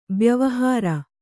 ♪ byavahāra